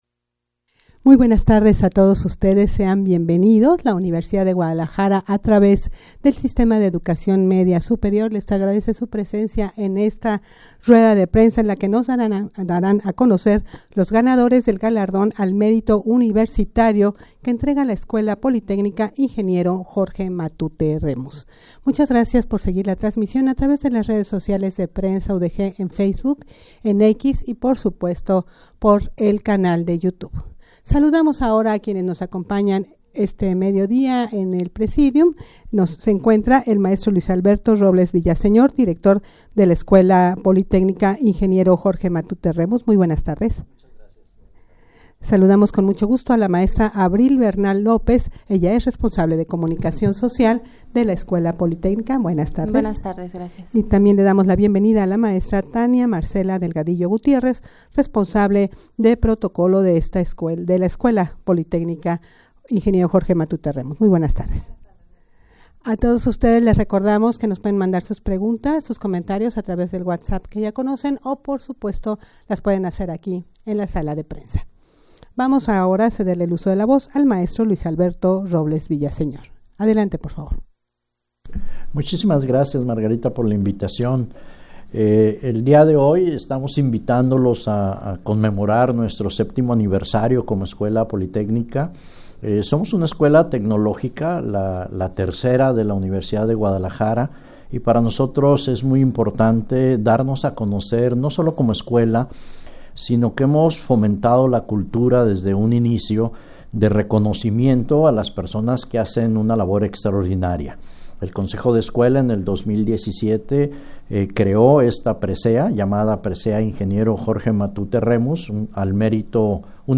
Audio de la Rueda de Prensa
rueda-de-prensa-para-dar-a-conocer-los-ganadores-del-galardon-al-merito-universitario.mp3